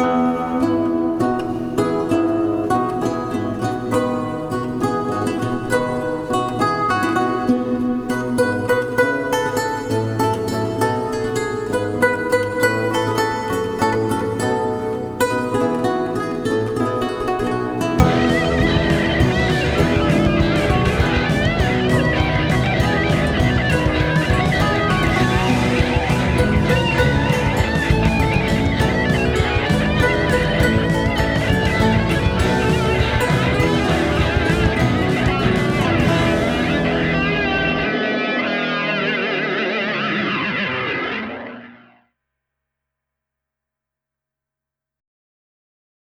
In 1968, about two centuries after Handel lived on Brook Street, another famous and influential musician moved into the flat next door … rock guitar virtuoso Jimi Hendrix.
Even more astounding was the discovery that directly connects the legacies of the two famous Brook Street residents: a recording of Mr. Hendrix improvising on the baroque composer’s musical motif.
Today’s Beautiful Music is Mr. Handel’s Hornepype played by Hendrix; you can listen to the audio clip below.
Hendrix-Handel-Hornpipe-final.wav